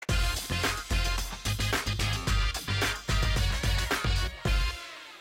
Play, download and share Correct Answer gde logica original sound button!!!!
correct-answer-gde-logica.mp3